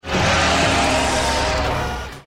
Techno-sentient_roar.mp3